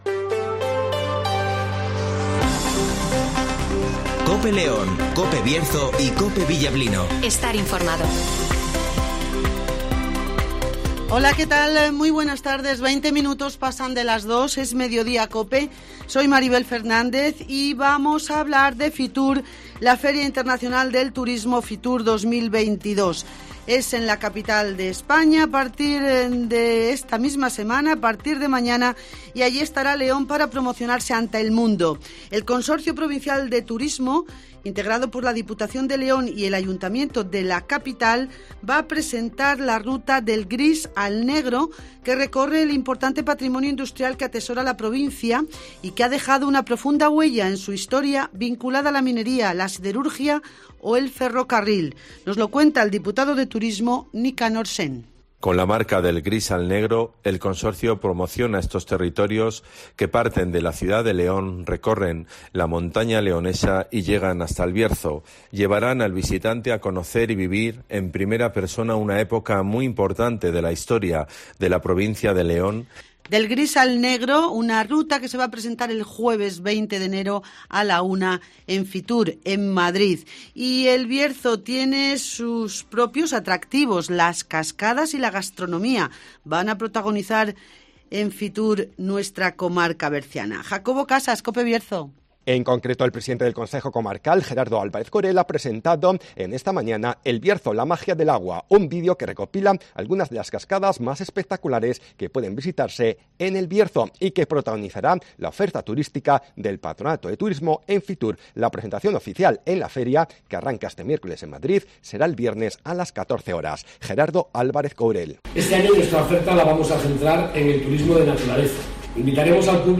- Nicanor Sen ( Diputado de Turismo )
- Luís Mariano Santos ( Procurador de la UPL )